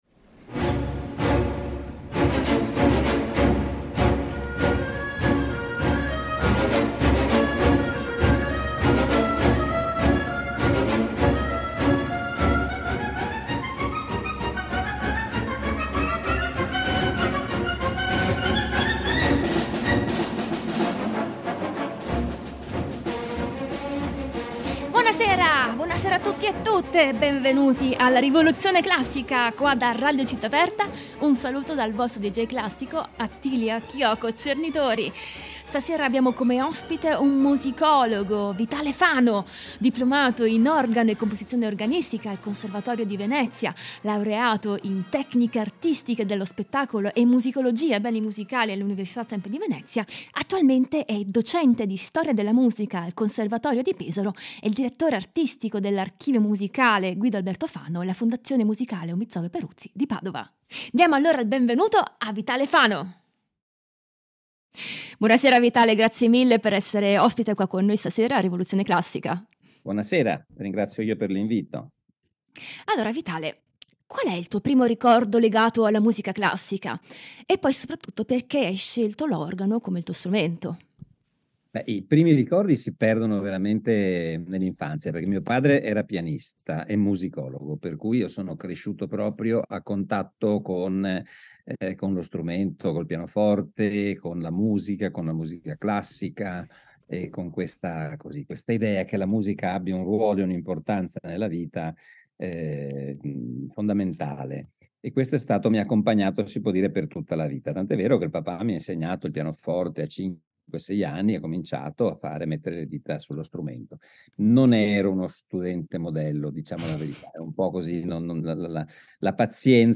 per violino e pianoforte
per pianoforte e orchestra